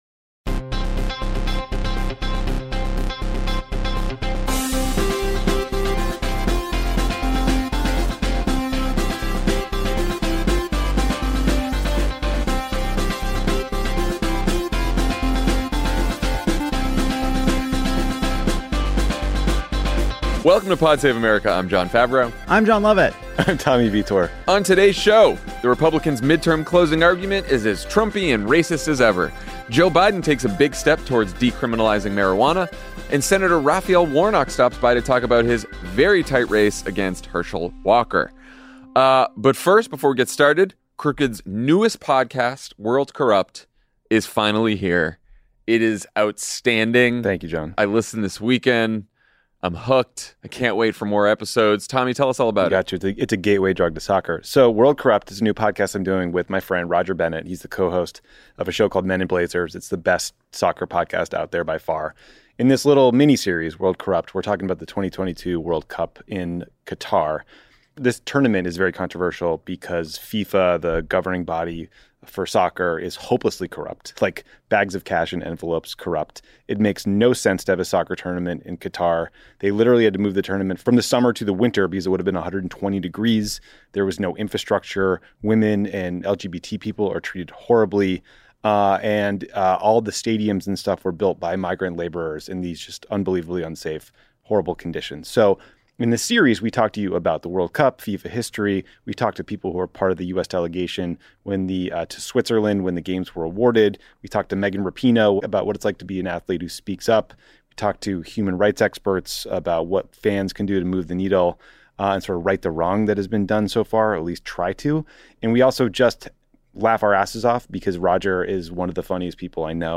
The Republican closing argument is as Trumpy and racist as ever. Joe Biden takes a big step towards decriminalizing marijuana. And Georgia Senator Raphael Warnock stops by to talk about his very close race against Herschel Walker.